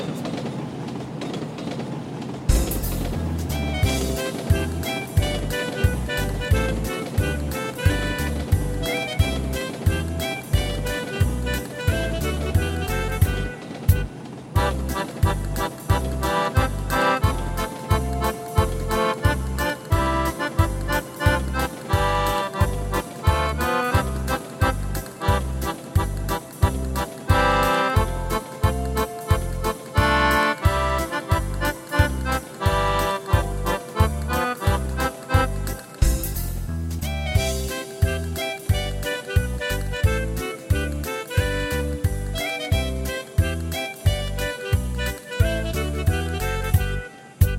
Akustik, Eisenbahn, Kuhglocken, böhmische Musikanten
Die Aufnahmen stammen noch aus dem Jahr 2012 - aufgenommen auf meinem alten Tyros 2 Keyboard von Yamaha nach dem Motto: